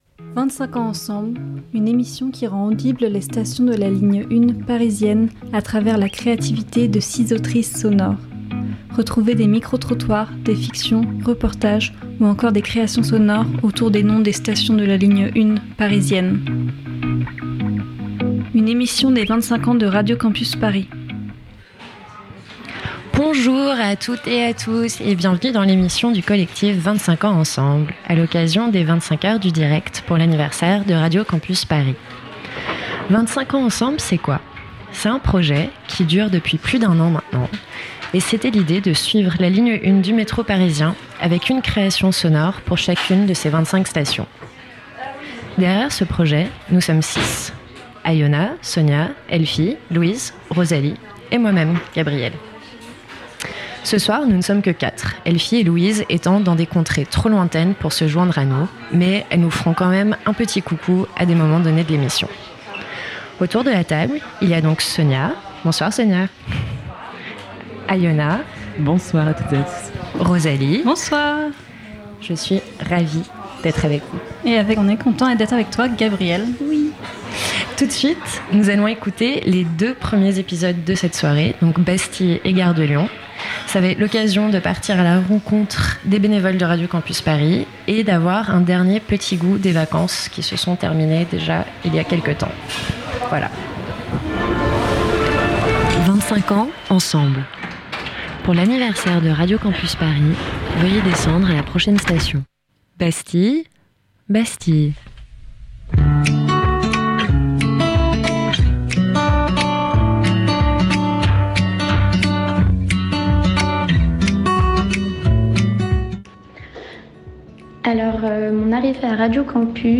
25h de direct en 24h - Radio Campus Paris